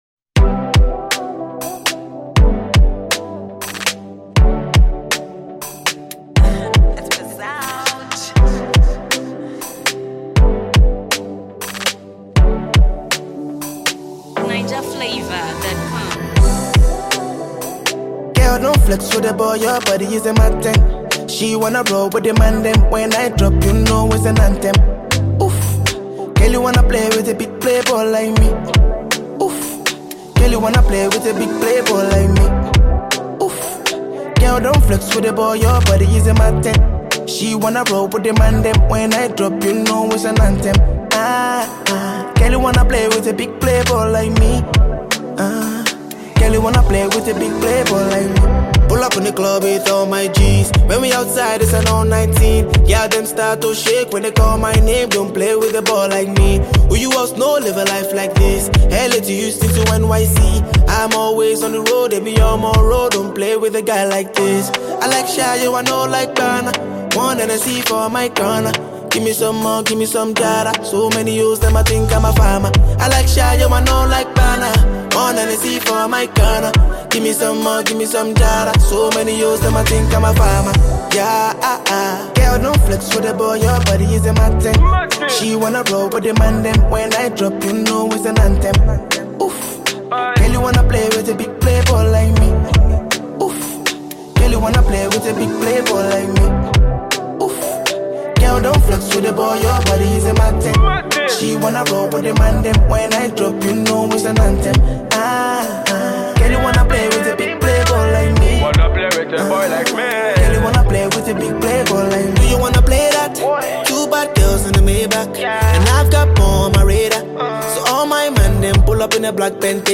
Naija Music Video
the Nigerian music crooner